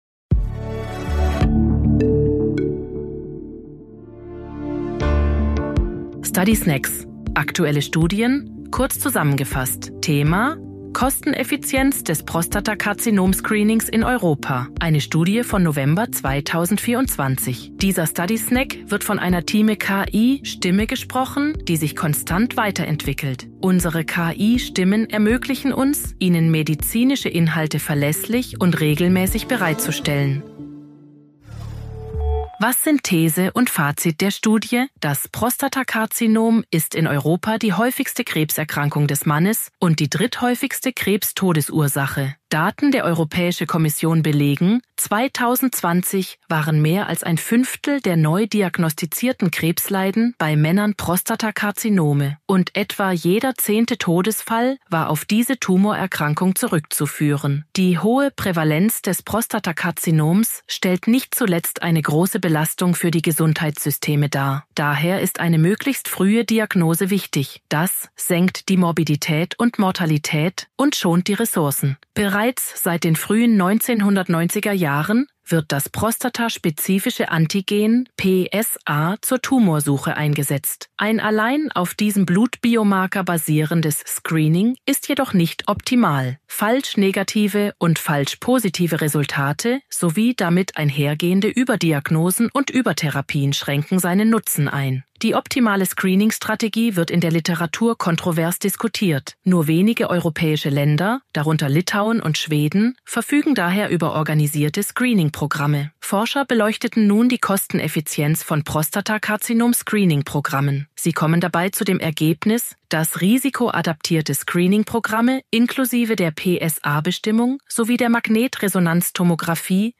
künstlicher Intelligenz (KI) oder maschineller
Übersetzungstechnologie gesprochene Texte enthalten